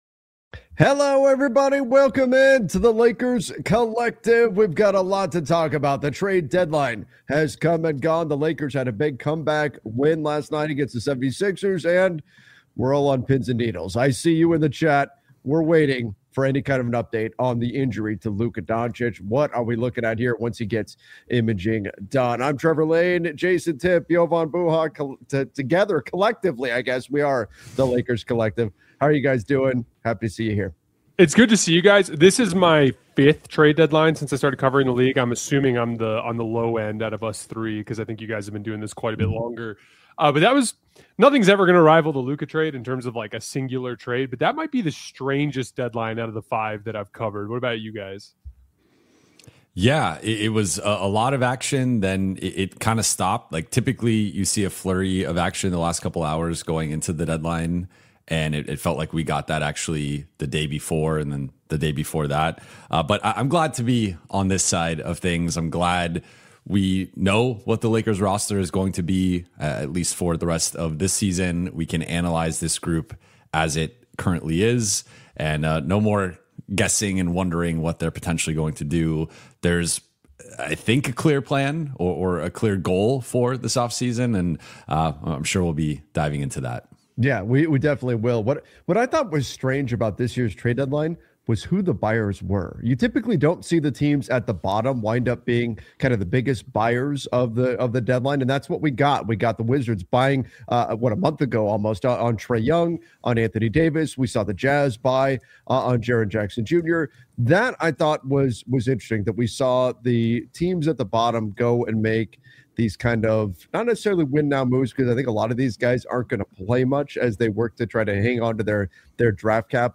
a weekly Los Angeles Lakers roundtable